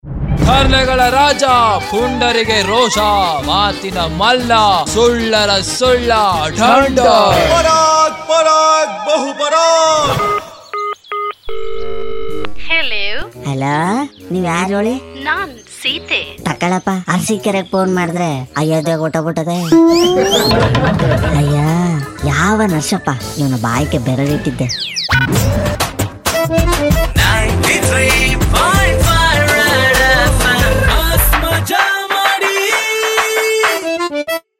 Most Loved Comedy Audio Clip That Makes YOU ROFL!!!